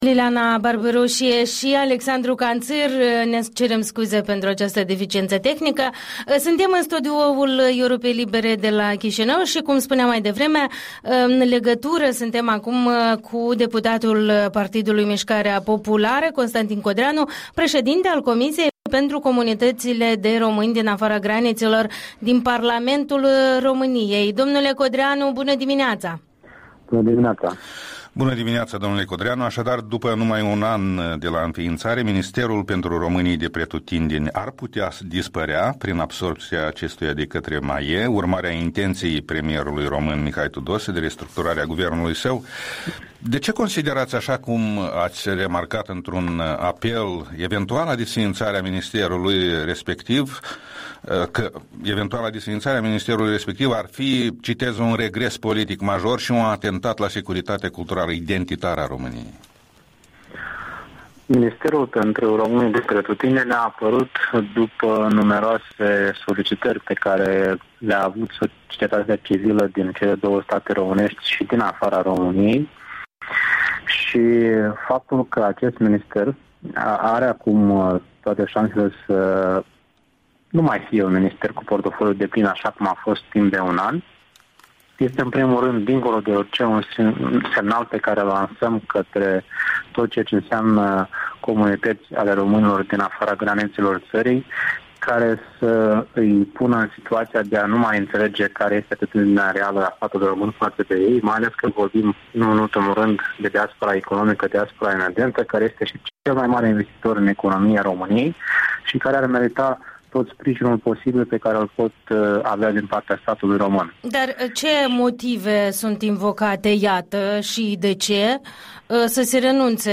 Interviul dimineții: cu deputatul român Constantin Codreanu